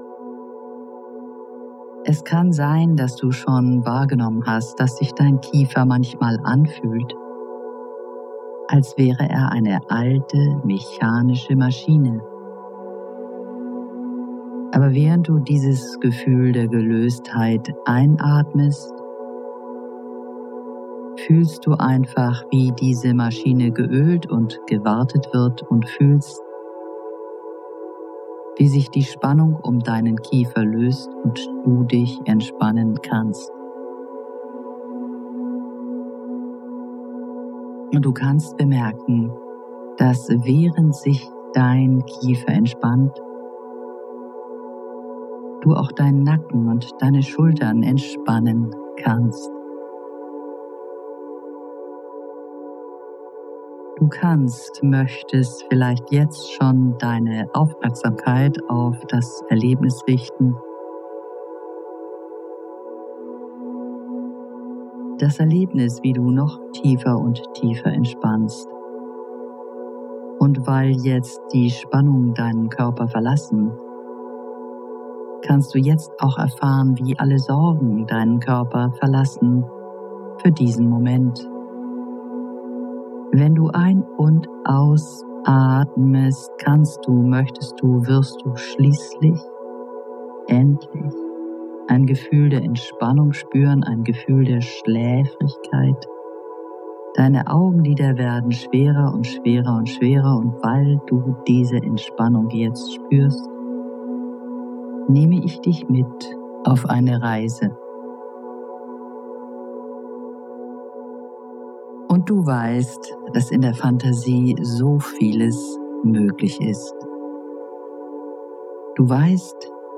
Was du in dieser speziellen Hypnosesitzung hören wirst, ist ein Vergleich, eine Metapher für die Beziehung zum Rauchen, die einer toxischen Beziehung sehr ähnlich ist.
• Musik: Ja;